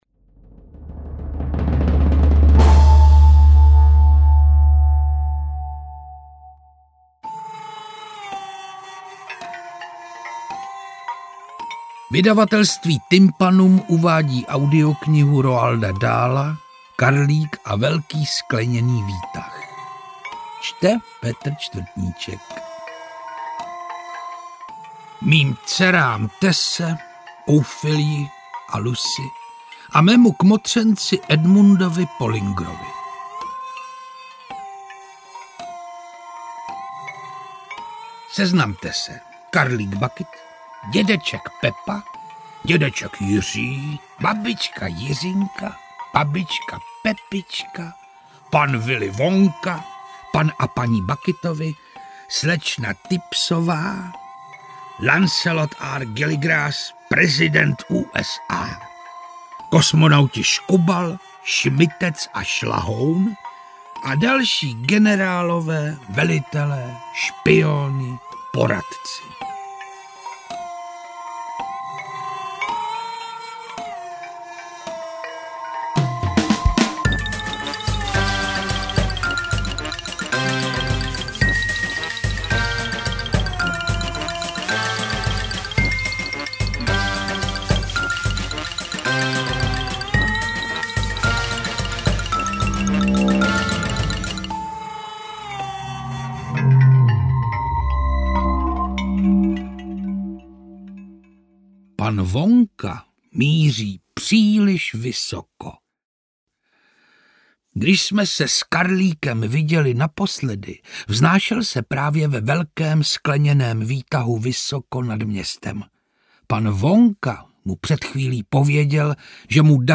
Interpret:  Petr Čtvrtníček
AudioKniha ke stažení, 27 x mp3, délka 4 hod. 33 min., velikost 249,0 MB, česky